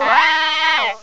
cry_not_mudbray.aif